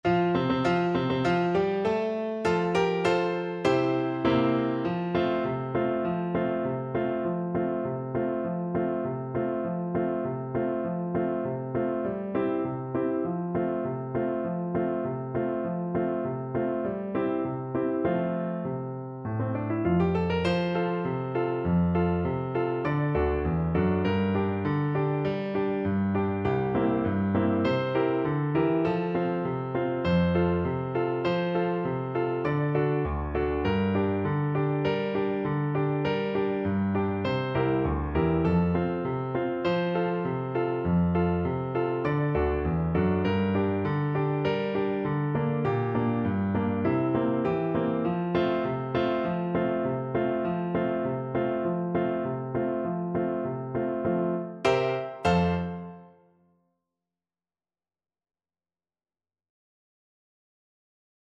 With energy =c.100
2/4 (View more 2/4 Music)